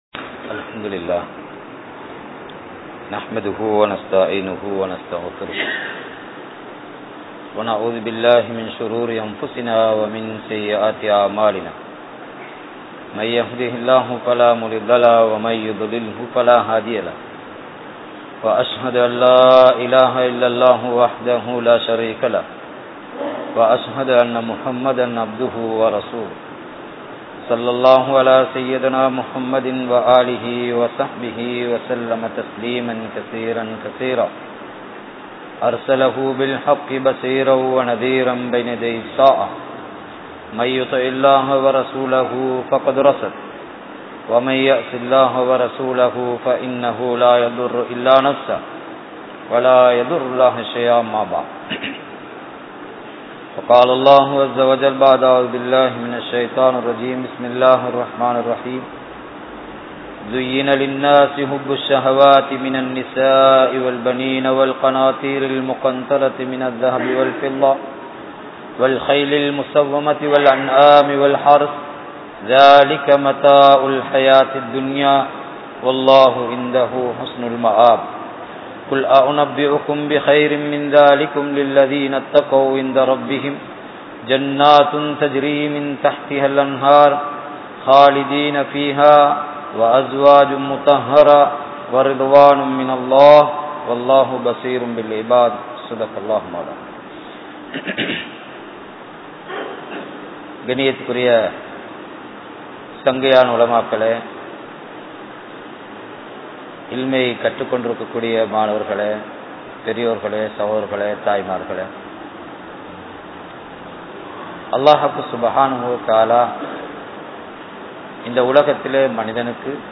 Kanavan Manaivien Kadamaihal (கணவன் மனைவியின் கடமைகள்) | Audio Bayans | All Ceylon Muslim Youth Community | Addalaichenai
Hussain Jumua Masjith